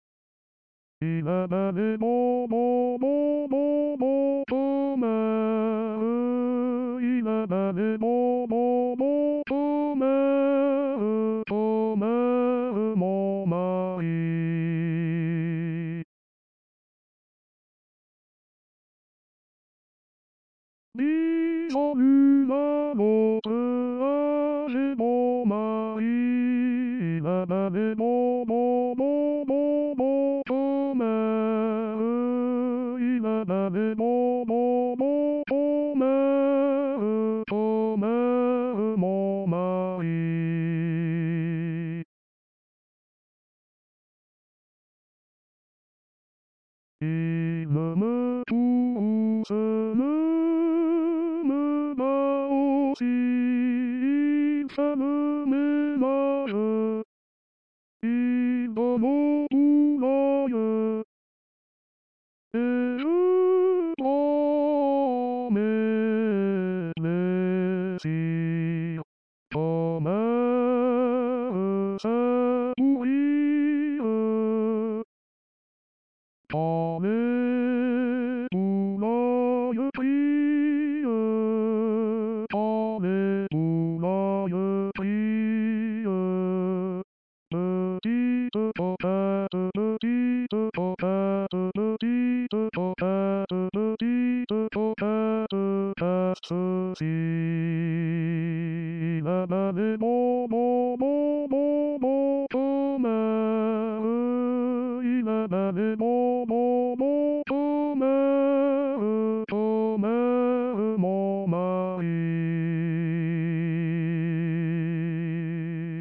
H= Hommes, S = Sopranes et T = Ténors; le signe + indique les fichiers où les autres voix sont en accompagnement.
il_est_bel_et_bon_t.mp3